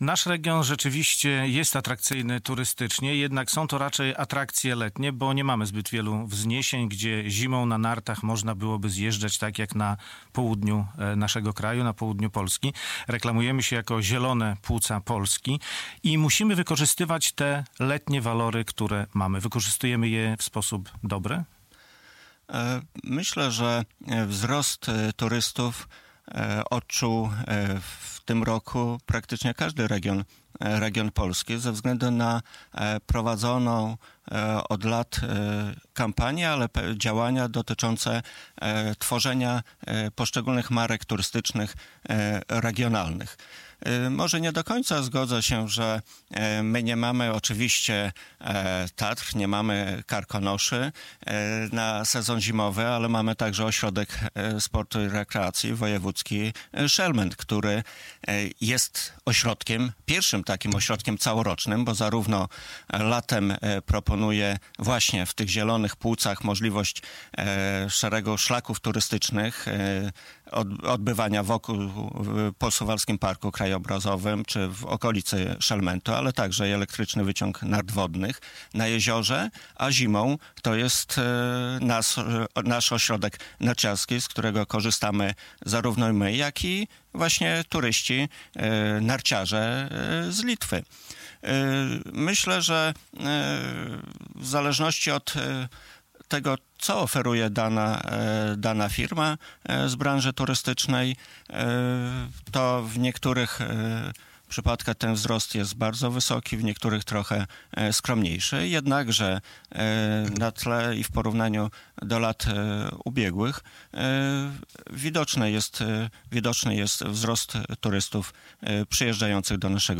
Radio Białystok | Gość | Bogdan Dyjuk - z Zarządu Województwa Podlaskiego